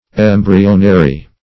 Meaning of embryonary. embryonary synonyms, pronunciation, spelling and more from Free Dictionary.
embryonary - definition of embryonary - synonyms, pronunciation, spelling from Free Dictionary Search Result for " embryonary" : The Collaborative International Dictionary of English v.0.48: Embryonary \Em"bry*o*na*ry\, a. (Biol.)